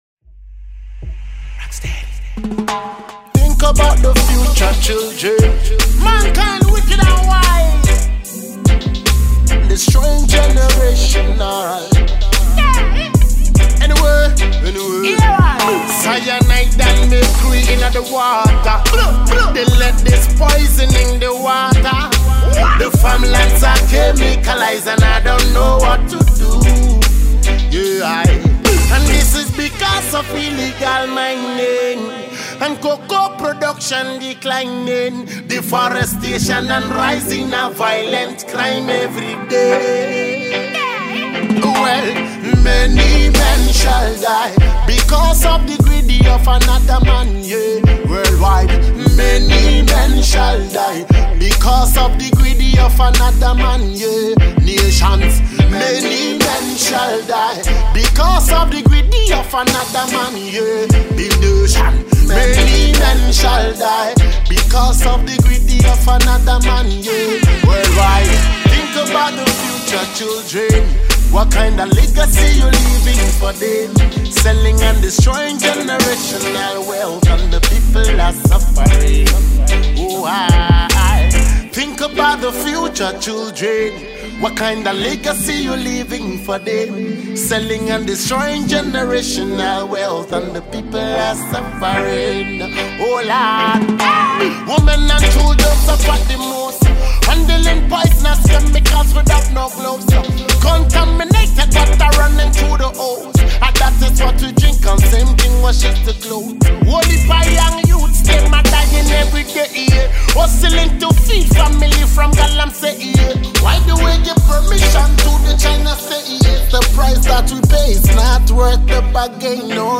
Ghanaian afrobeat-dancehall artist